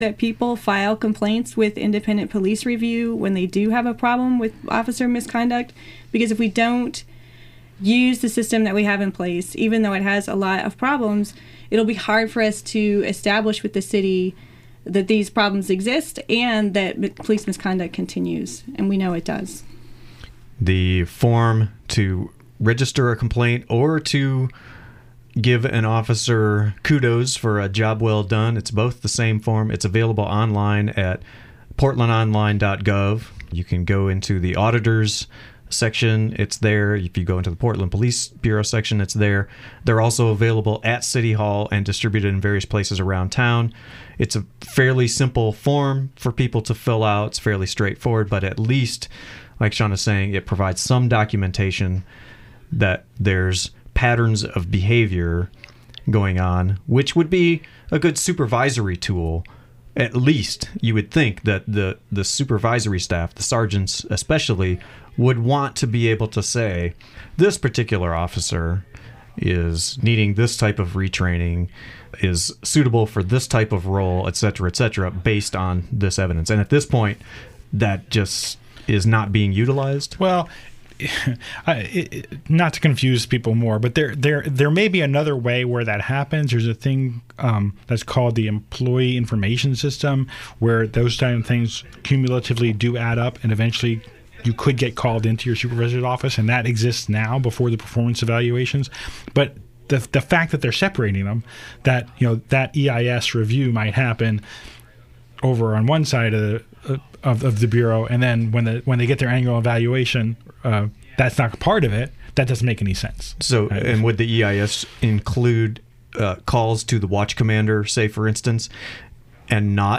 Download audio file In light of the rally happening at the Portland Public School District Building as Labor Radio airs today, we're remixing some of our favorite moments in Labor Radio reporting: excerpts from the Jefferson student demonstration on Friday, interviews with students, teacher...